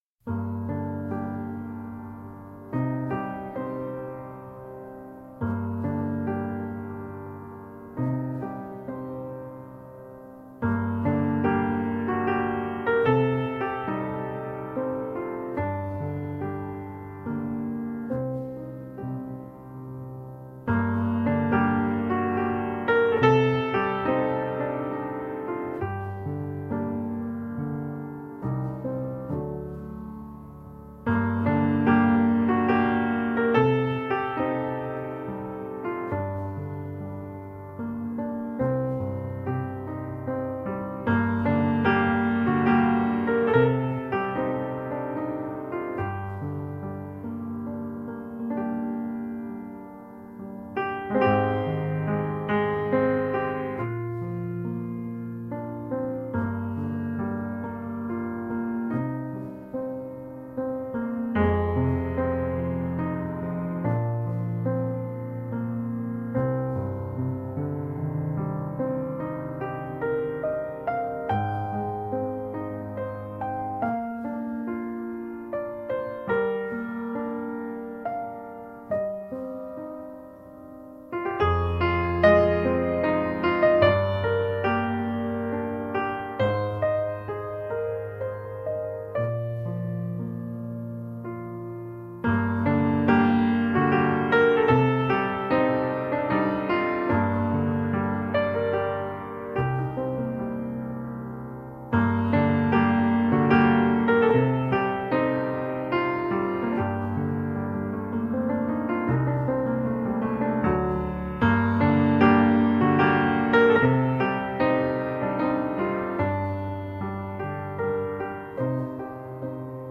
Tagged as: New Age, Classical, New Age Piano